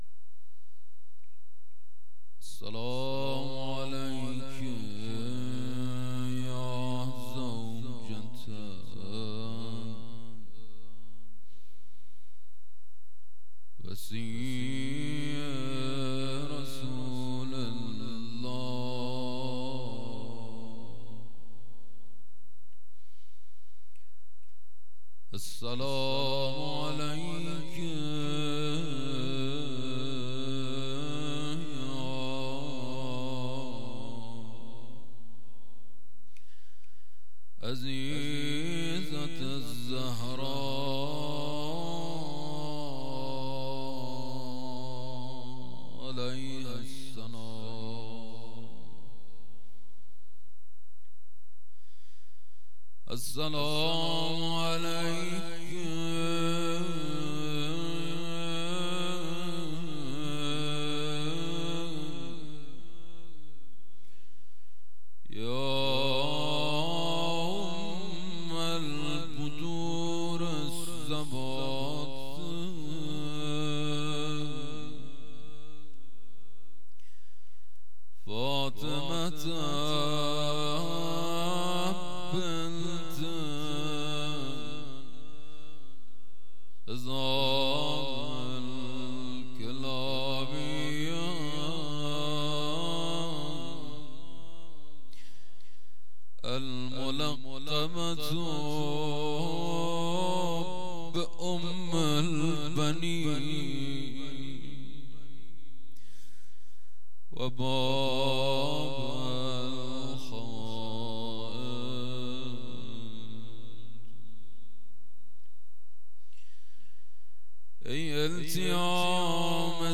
خیمه گاه - بوتراب علیـہ السلام - روضه وفات حضرت ام البنین (س)
مراسمات هفتگی